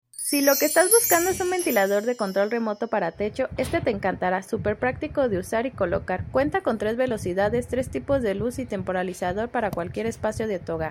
Ventilador de techo con control